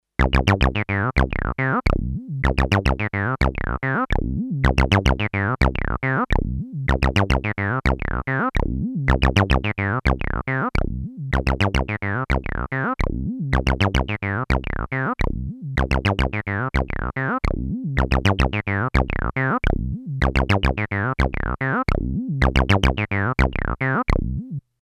Both the TB303 and TM3030 are being sequenced via MIDI from a Sequentix P3 sequencer.
The P3 plays the pattern alternating a bar at a time between the two instruments.
Initially, both instruments are panned to centre, but after a few bars, one is panned to the left and the other right, to show that it isn't just one instrument playing the same pattern.
Note that both the TB303 and TM3030 used have some mods from the stock TB303 sound.